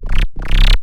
55-FROG   -L.wav